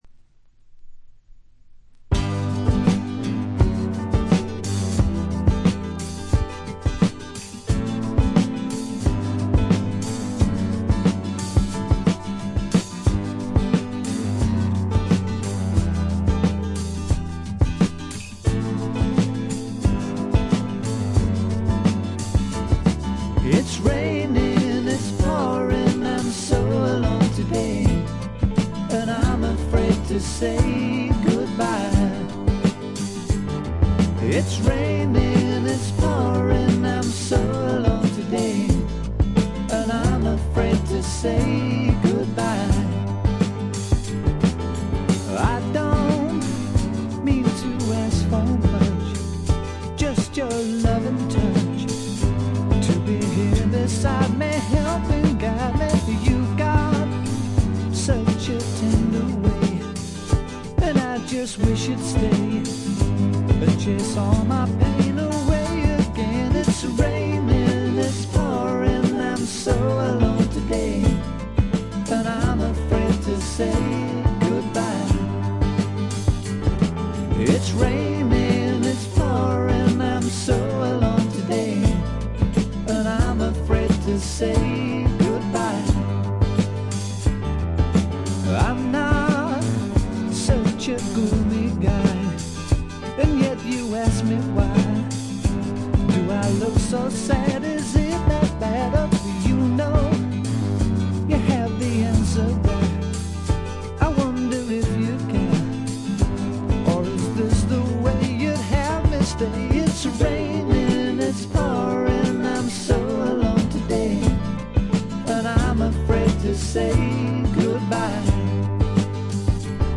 A面はほとんどノイズ感無し。
味わい深い美メロの良曲が連続する快作。
試聴曲は現品からの取り込み音源です。